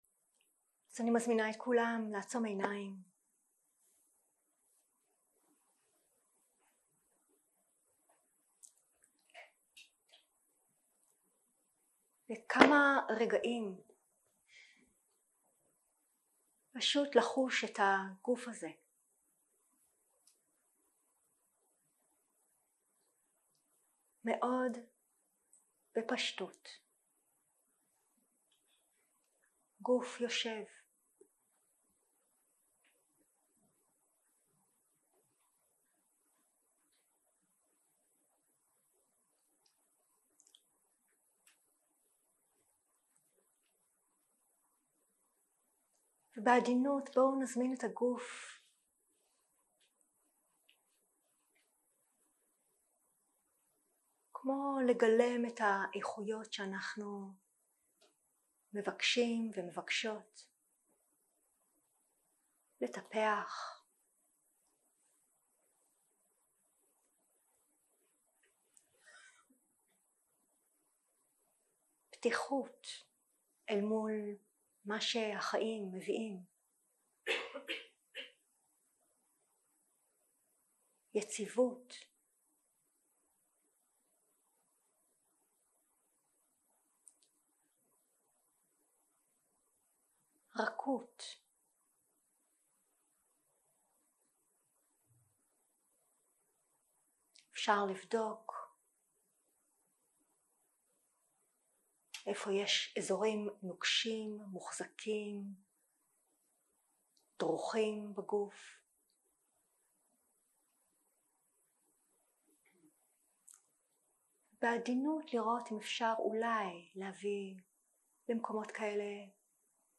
יום 2 - צהרים - מדיטציה מונחית - תשומת לב לגוף ולחץ השני - הקלטה 3 0:00 0:00 סוג ההקלטה: Dharma type: Guided meditation שפת ההקלטה: Dharma talk language: Hebrew